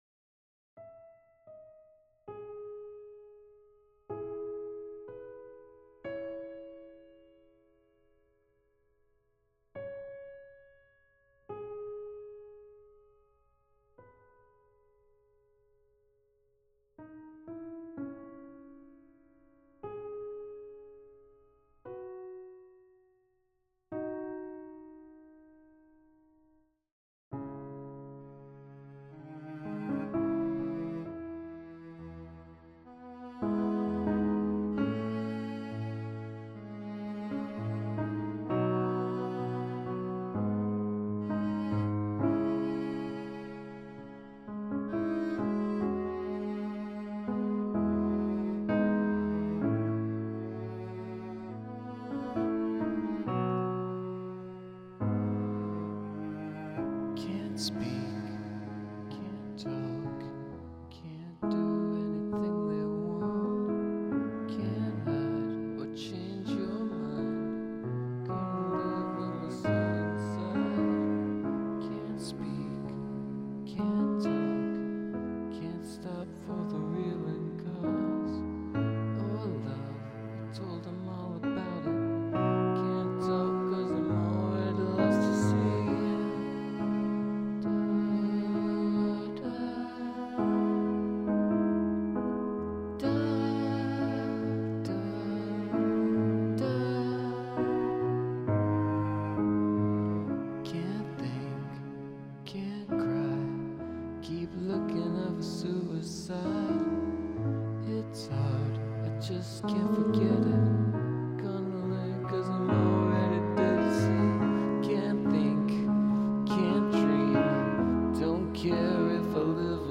piano cover
It sort of works with the song.
Filed under: Song Cover | Comments (3)
The dropouts happen at dramatic moments, too!